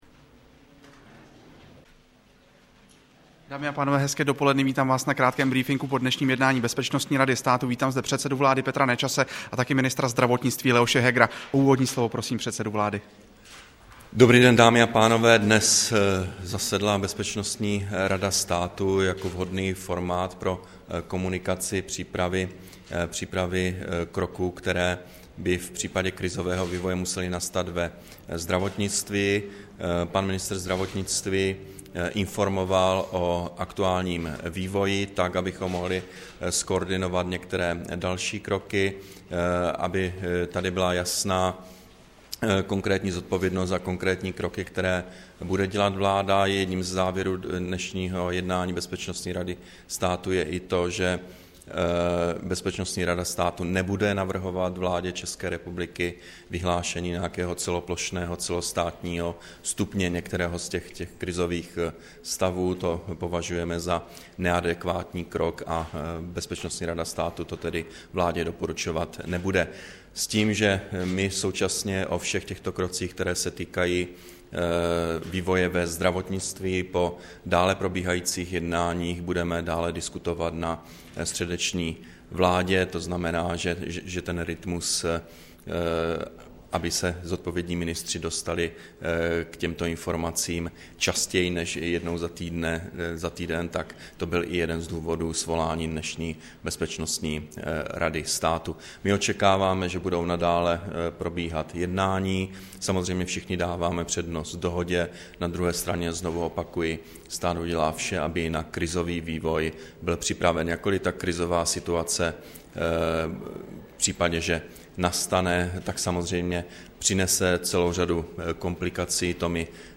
Tiskový brífink premiéra a ministra zdravotnictví po jednání Bezpečnostní rady státu, 14. února 2011